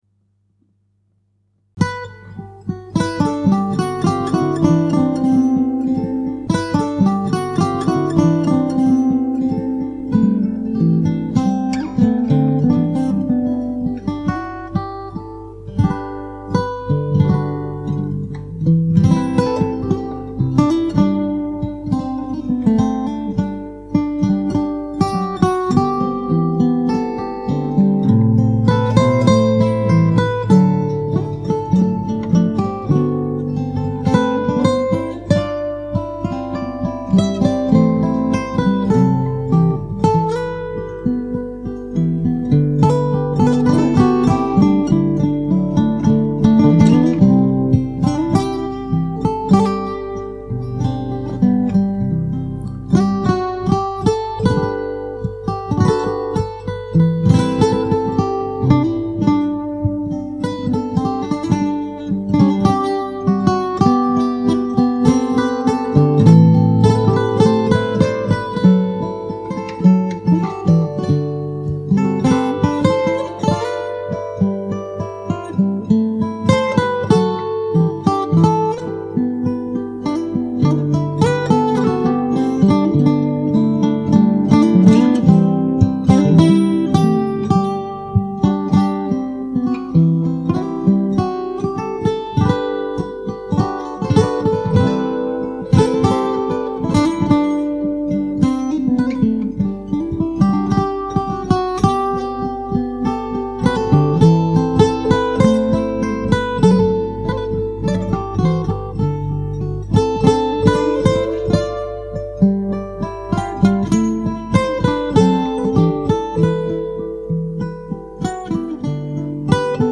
An instrumental version
several layers
guitar